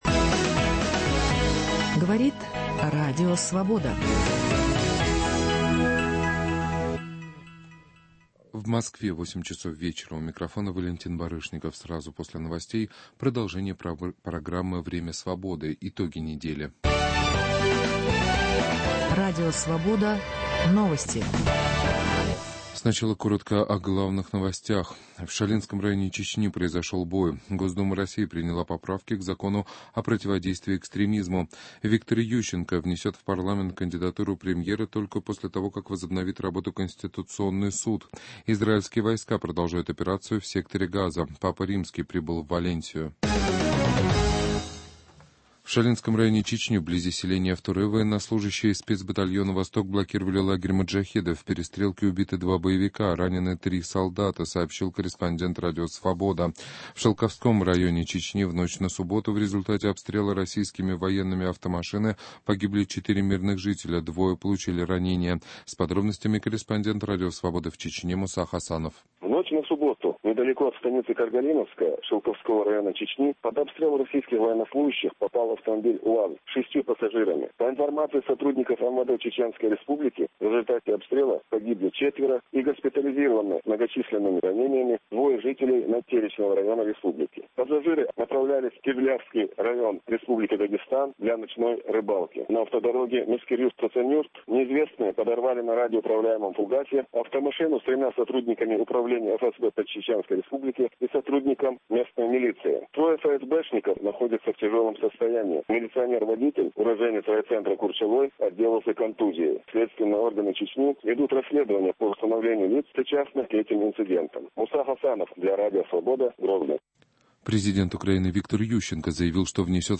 Что такое "контрсаммит" и как антиглобалисты намереваются участвовать во встрече лидеров "большой восьмерки" в Петербурге? Политический переворот на Украине: почему Александр Мороз изменил "оранжевой коалиции"? В прямом эфире программы - лидер партии Демократический союз Валерия Новодворская.